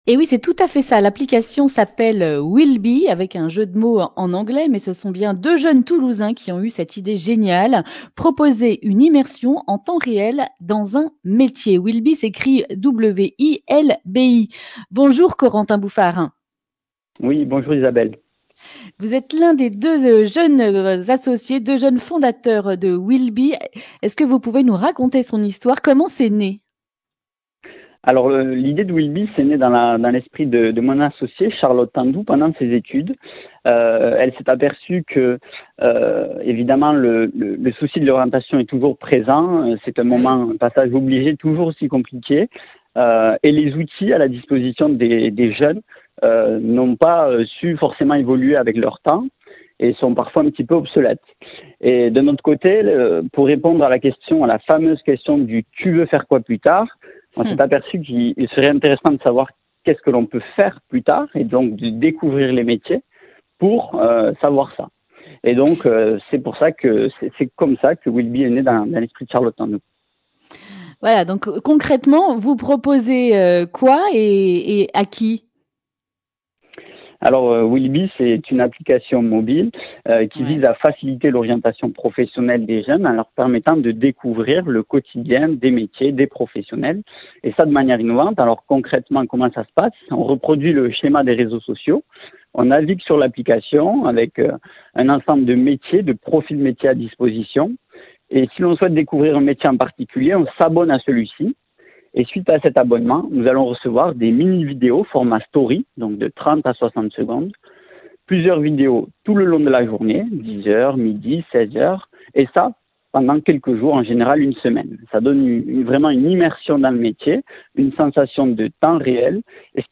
mardi 3 novembre 2020 Le grand entretien Durée 10 min